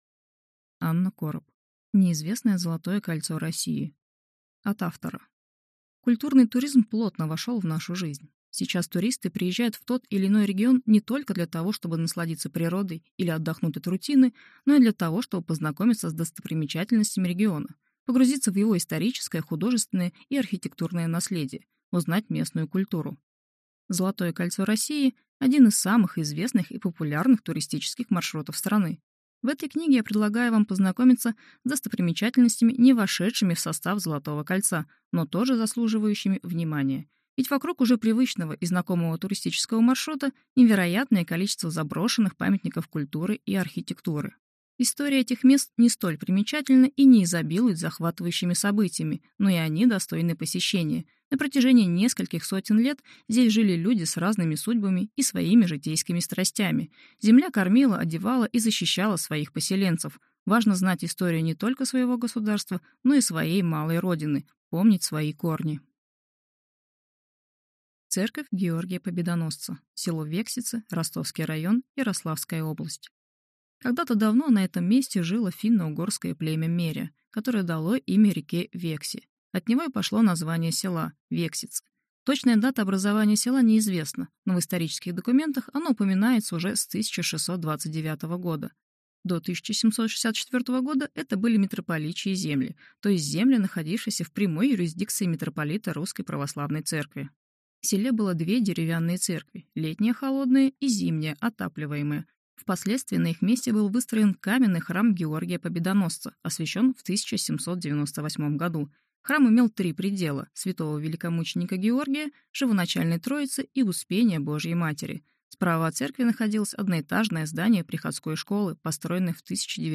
Аудиокнига Неизвестное Золотое кольцо России | Библиотека аудиокниг